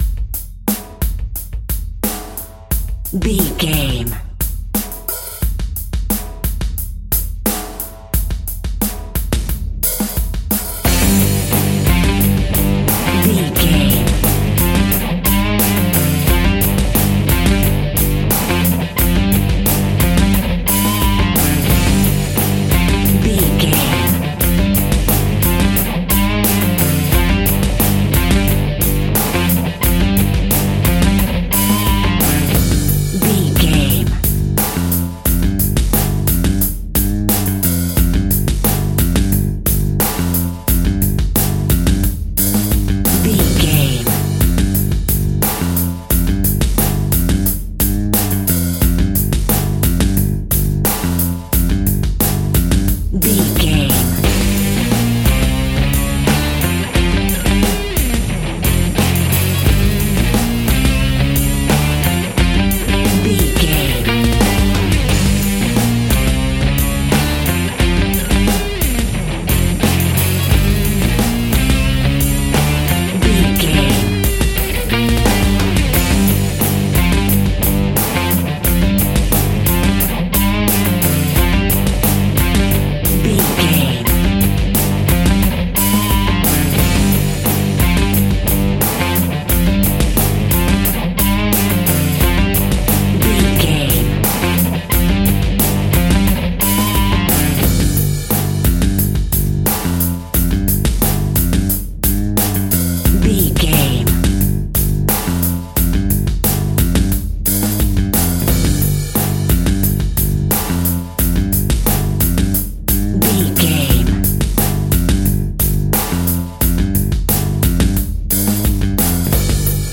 Powerful Rock Music Track Alt Mix.
Epic / Action
Aeolian/Minor
heavy metal
blues rock
distortion
instrumentals
Rock Bass
heavy drums
distorted guitars
hammond organ